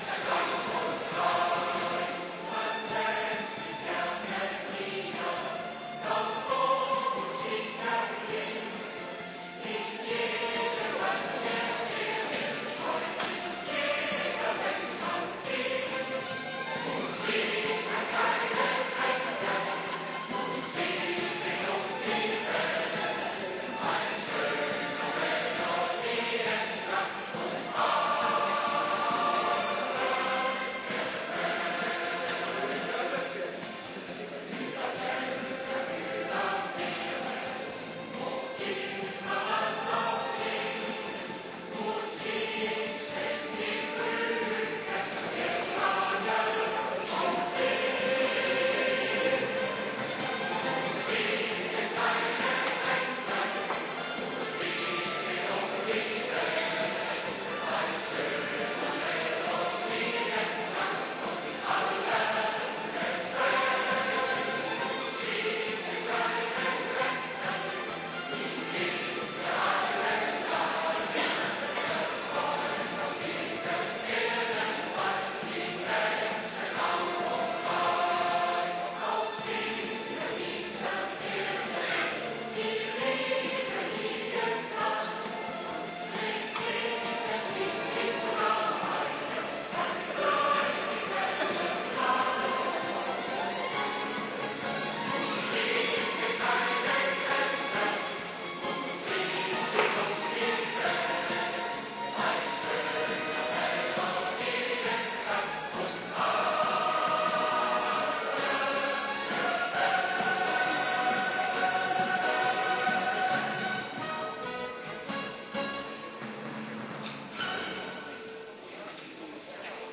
Adventkerk Zondag week 43